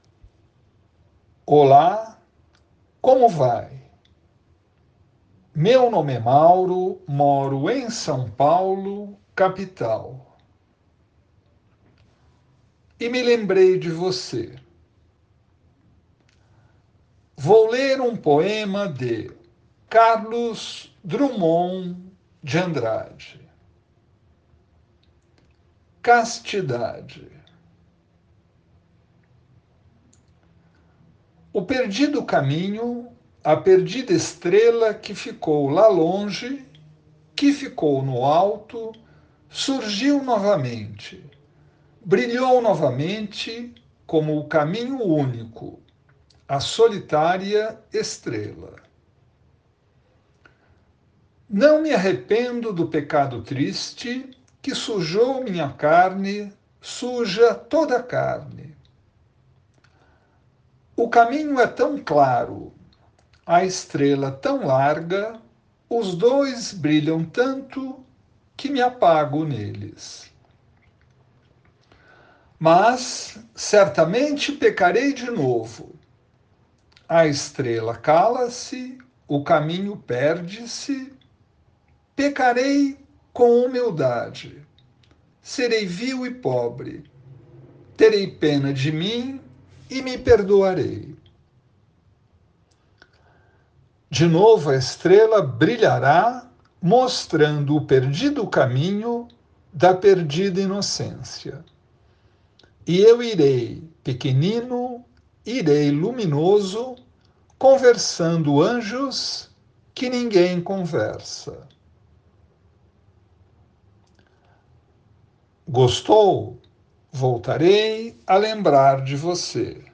Poema Português